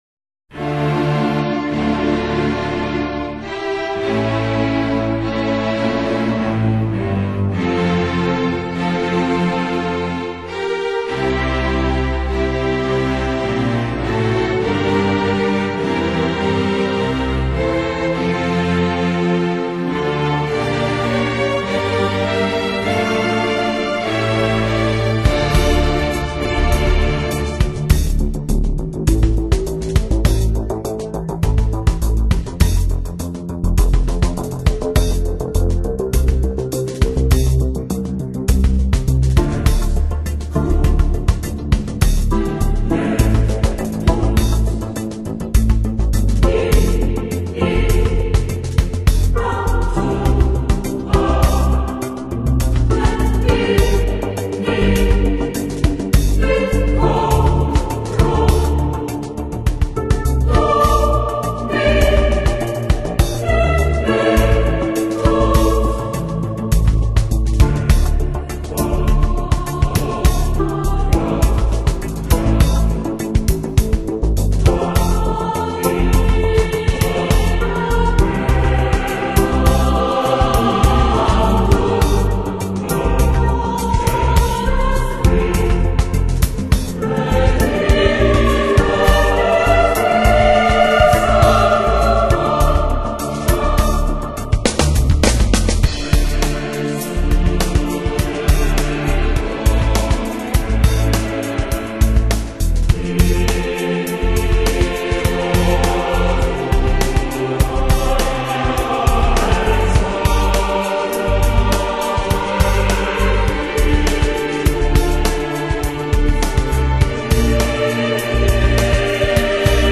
音乐类别：天籁和声
编曲和经典的歌剧旋律。催眠的人声、管弦乐的演奏和令人神魂颠倒的旋律这三者
的结合创造出了全新的声音：动人、感性、有力。
另外，专辑中的人声并非采样。
元素和强劲流畅的音乐结合创造出了一次令人兴奋的灵魂和心脏的双重体验。